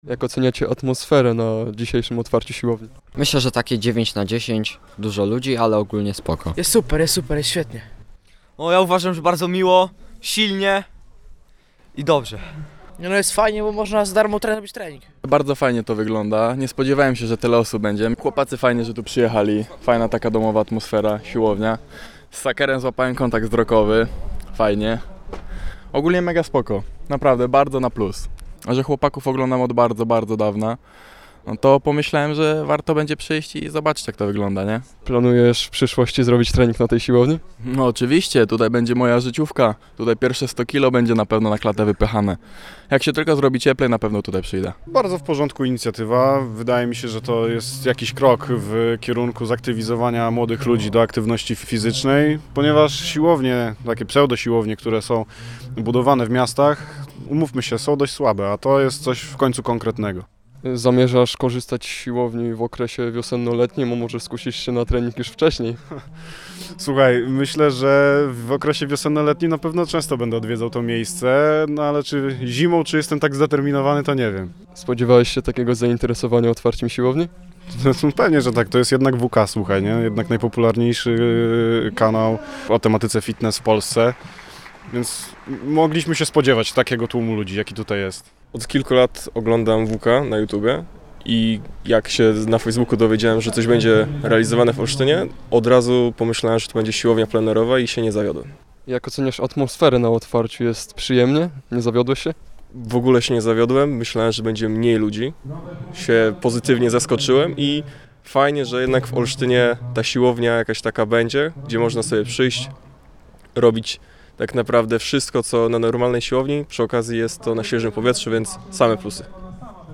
2211-MA-silownia-sonda.mp3